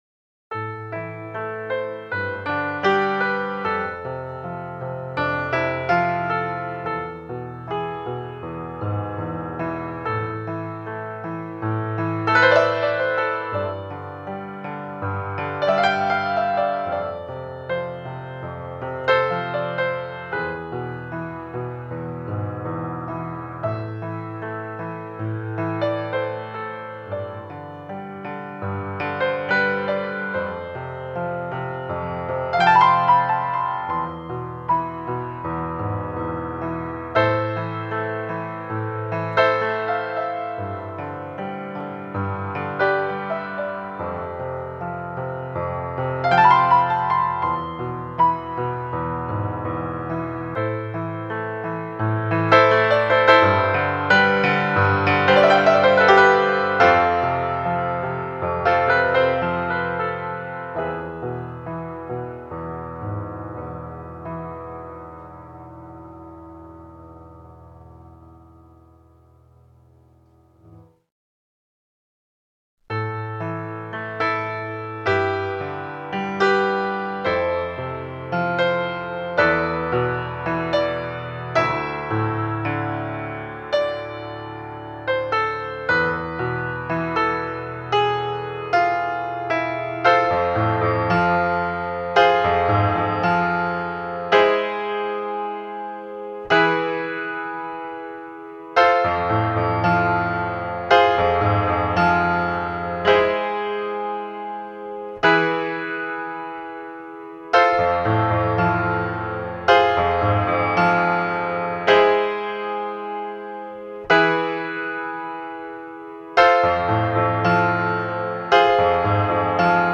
musique de film